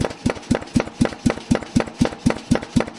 工作的声音 铁匠ed1. 电锤 " 电锤 Billeter Klunz 50kg 量化的排气口12击
描述：Billeter Klunz 50公斤排气口量化为80bpm（原点122bpm），12次点击。使用Tascam DR40和Sony ECMNV1以24位96kHz录制。
标签： 1巴 80bpm 量化 锻造 电机 压力 空气 排气口 金属制品 莱德-klunz 工具 劳动 工作 工艺品 功率锤 铁匠
声道立体声